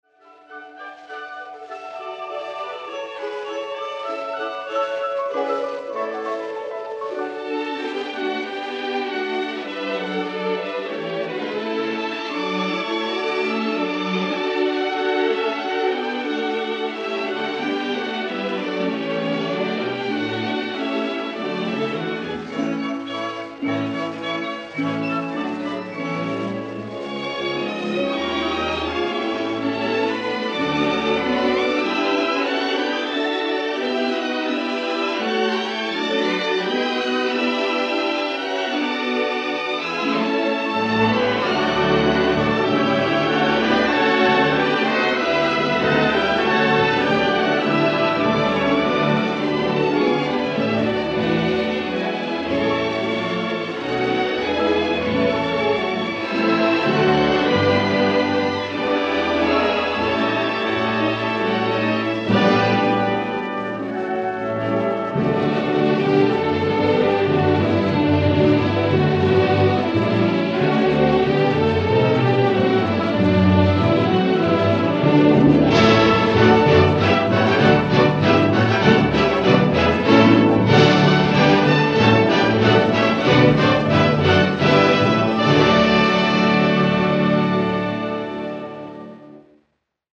With Chorus